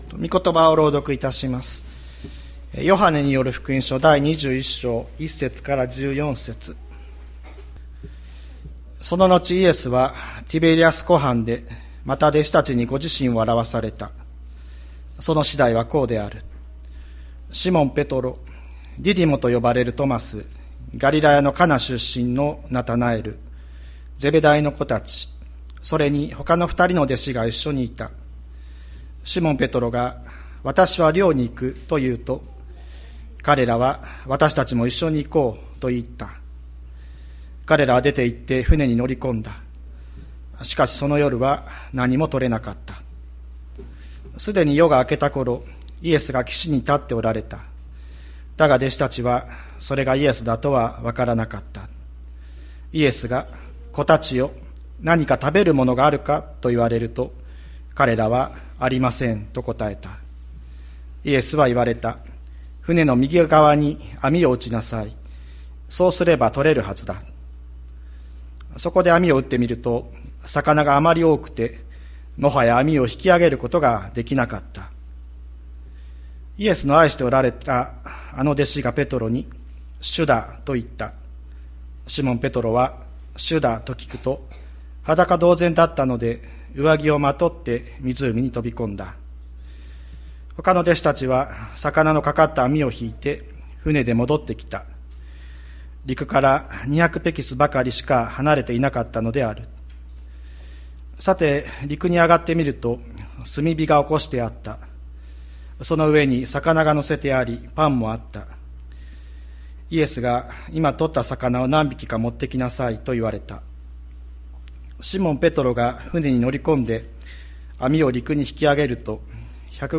2023年05月07日朝の礼拝「さあ、喜びの食卓へ！」吹田市千里山のキリスト教会
千里山教会 2023年05月07日の礼拝メッセージ。